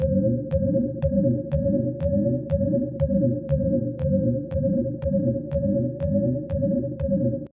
ENEMY_EDEN_PILLAR_PROJECTILETRAVEL.ogg